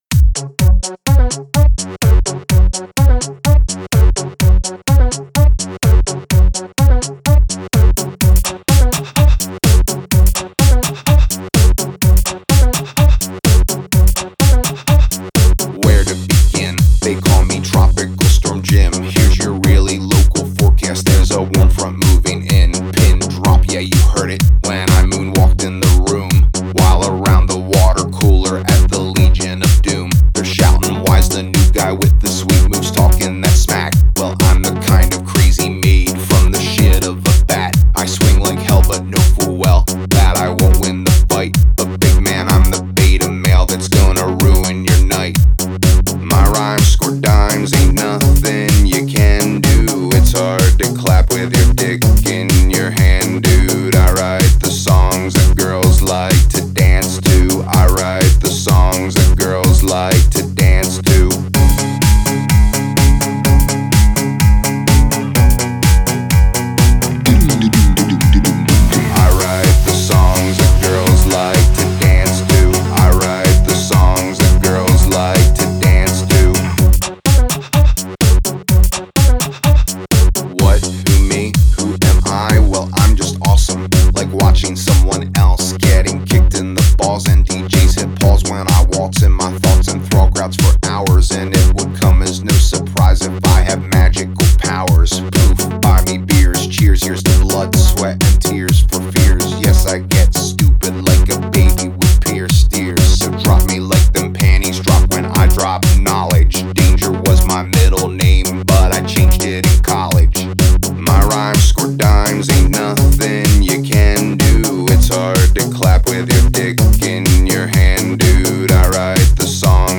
Жанр: Alternative, Rock